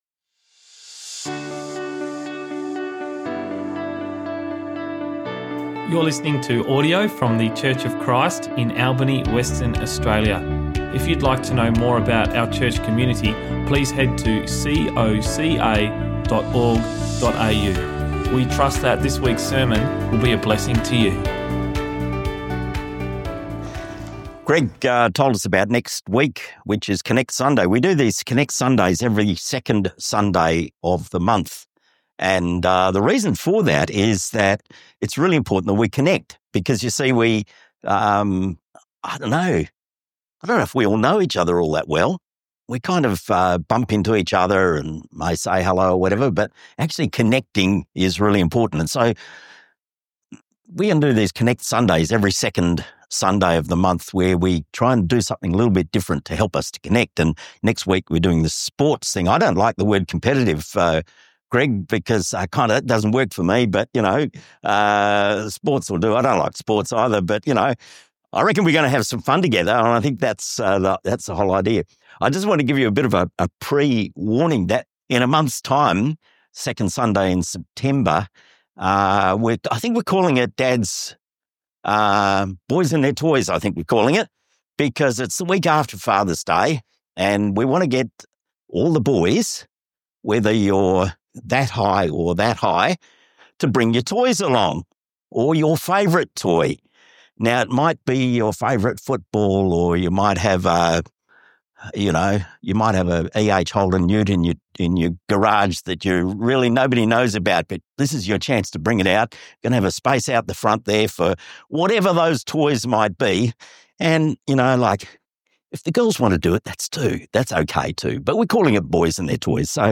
Sermons | Church of Christ Albany